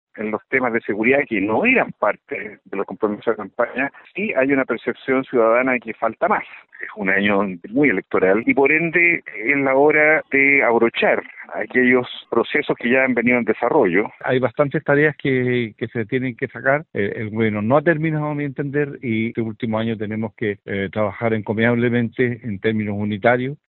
Así lo indicó el senador PS, Juan Luis Castro; mientras que su par, el senador Gastón Saavedra, apeló por la unidad en el sector para avanzar.